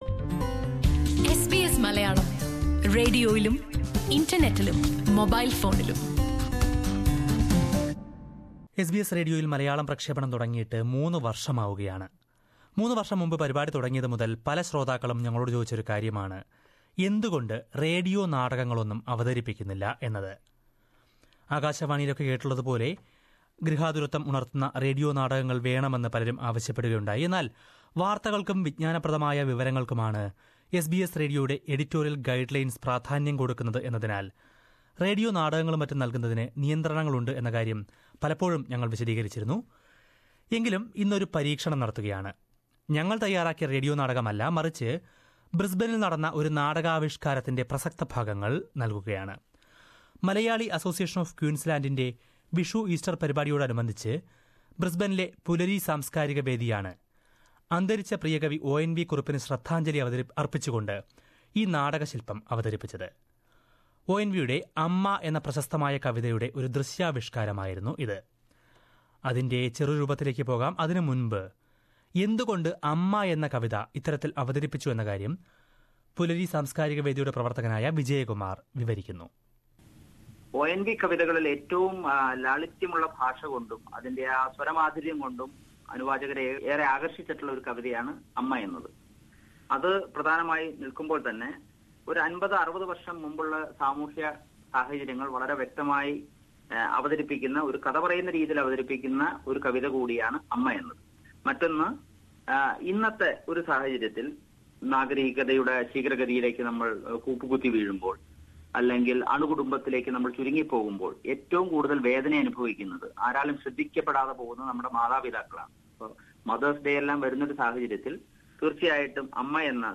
Pulari Samskarika Vedi in Brisbane has paid a special tribute to poet ONV Kurup, by staging one of his most famous poems. The musical drama based on ONV's 'Amma' was enacted at the Vishu-Easter celebration of Malayalee Association of Queensland.